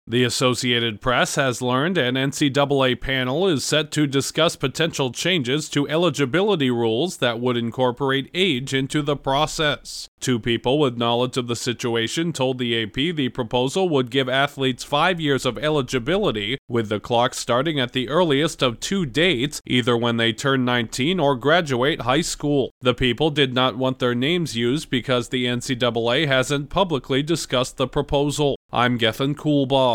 College sports’ governing body is considering closing loopholes on athletes’ eligibility windows. Correspondent